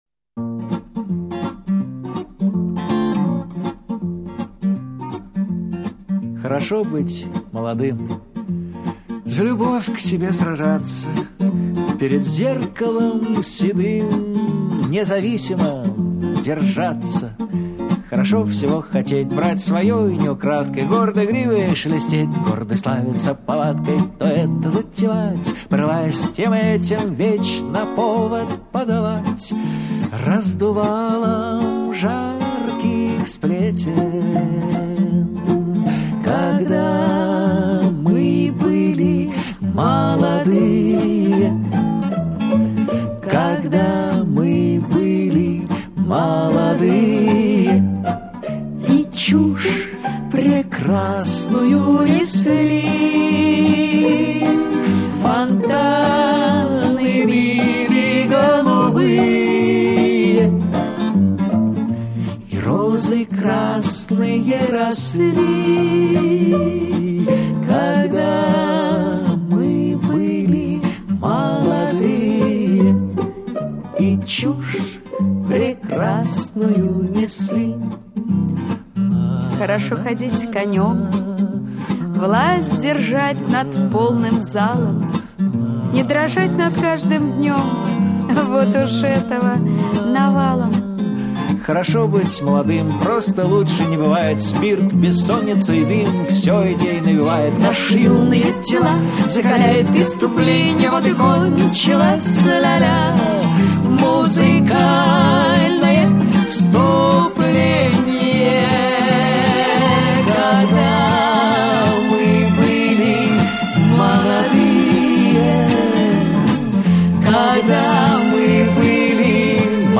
на гитаре играет очень культурно